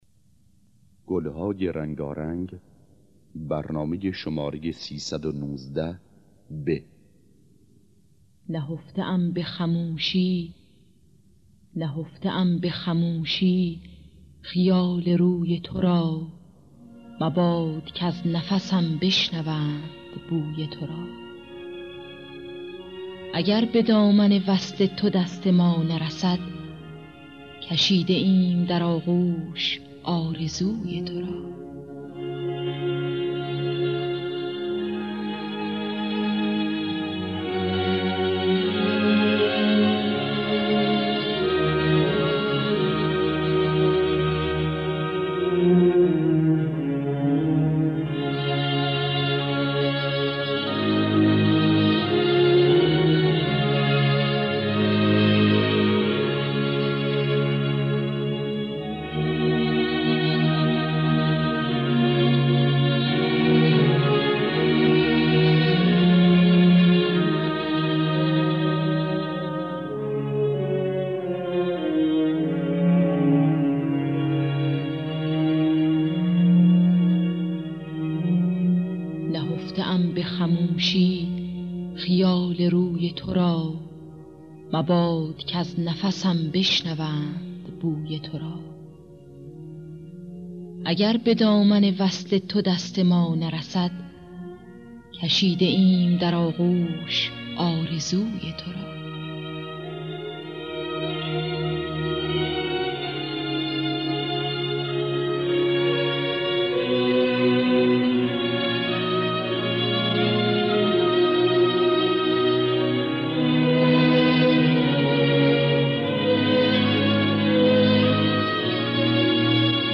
دانلود گلهای رنگارنگ ۳۱۹ب با صدای الهه، عبدالوهاب شهیدی در دستگاه دشتی. آرشیو کامل برنامه‌های رادیو ایران با کیفیت بالا.
خوانندگان: الهه عبدالوهاب شهیدی نوازندگان: جواد معروفی احمد عبادی